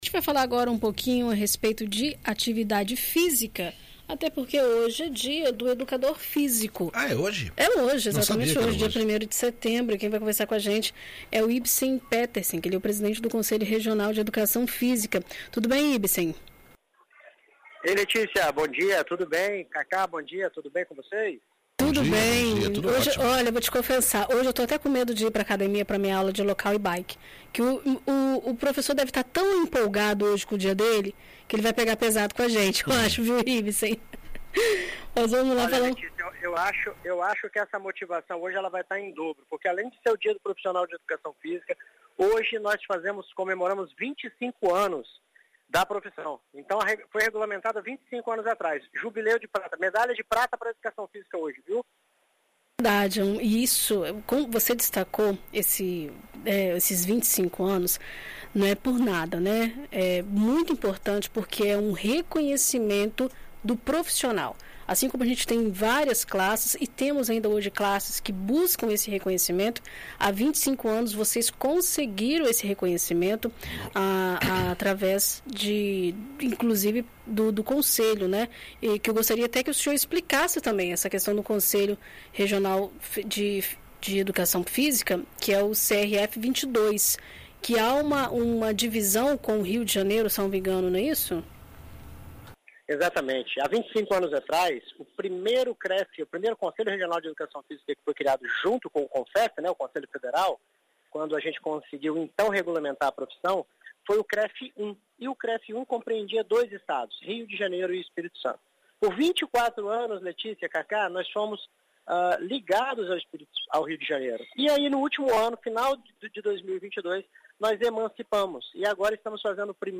Hoje é dia do educador físico! Também é celebrada a regulamentação da profissão que ocorreu há 25 anos. A profissão tem crescido ao longo dos anos e se adaptado às necessidades da sociedade, principalmente relacionadas à saúde e a prevenção de doenças. Em entrevista à BandNews Espírito Santo nesta sexta-feira